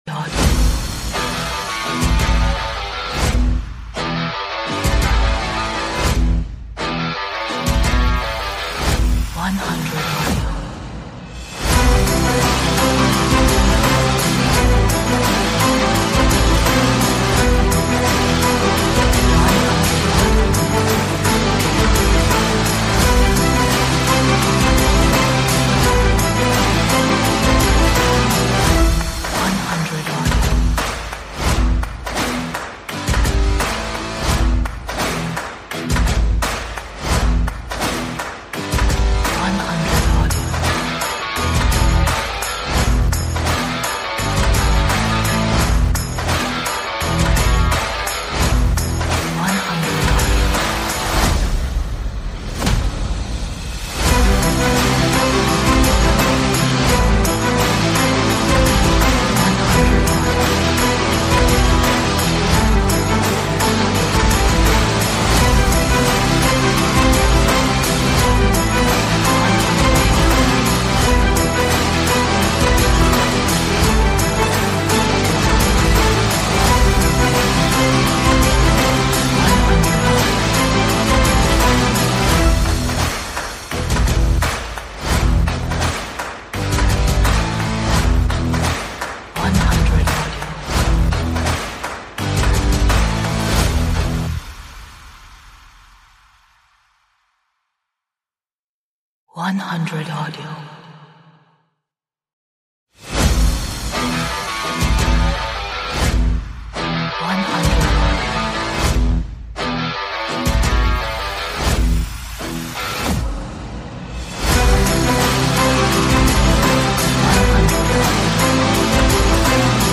is powerful track with aggressive guitar riffs and